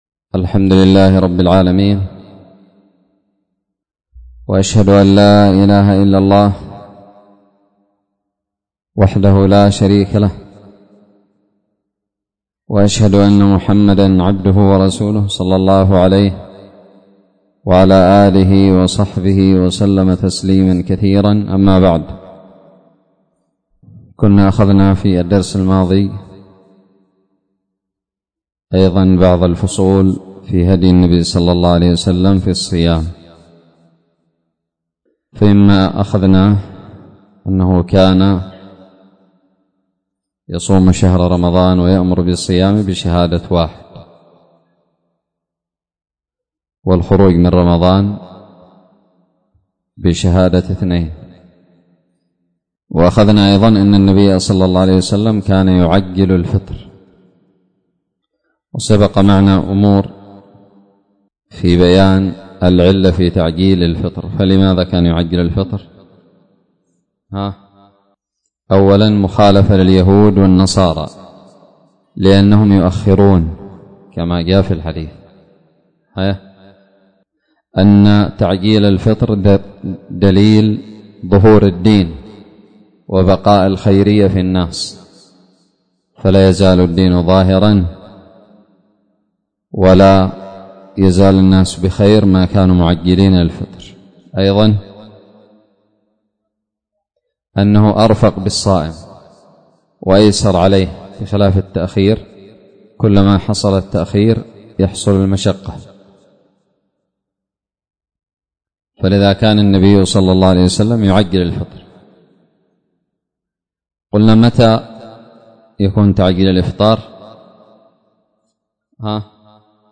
الدرس السادس من التعليق على فصل هدي النبي صلى الله عليه وسلم في الصوم من زاد المعاد
ألقيت بدار الحديث السلفية للعلوم الشرعية بالضالع